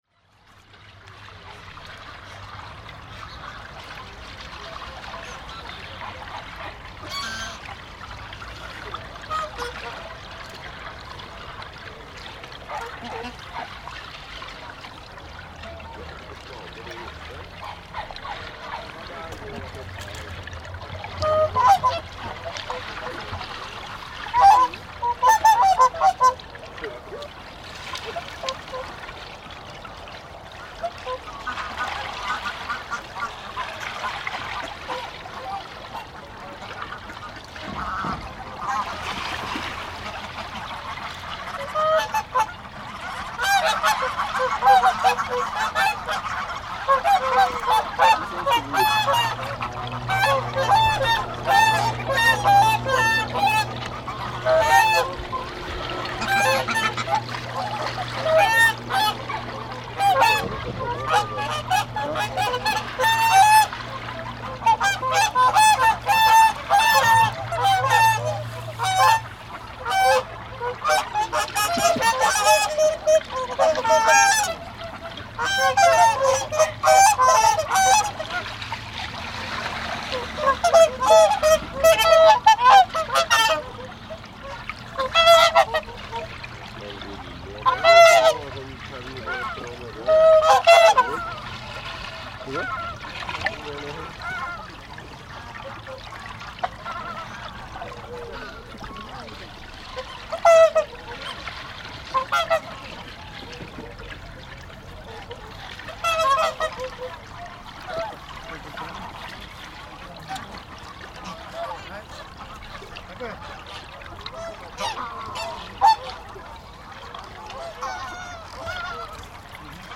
Often people feed this birds with bread so outburst is normal when birds grasp the breadcrumbs.
This recordings was made simultaneously both above and under water on four tracks.
In both worlds. Above and in the pond.
air_and_water.mp3